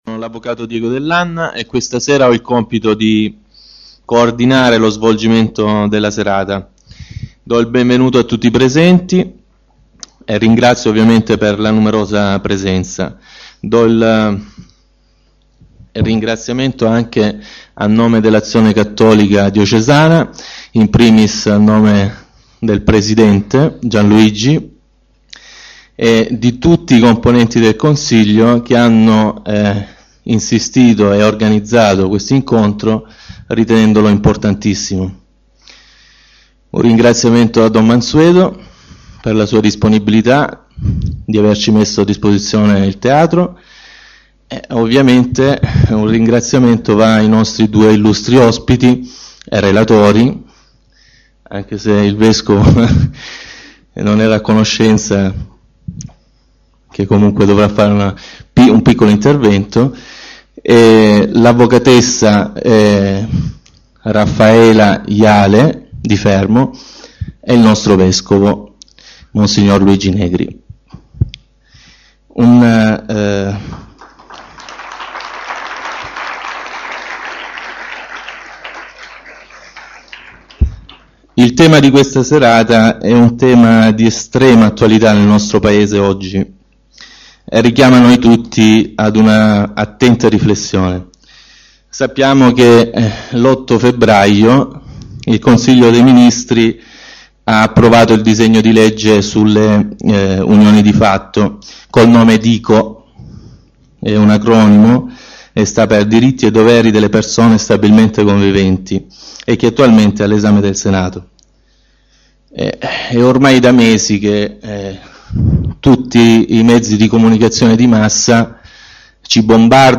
Convegno su: Dico: è ancora famiglia?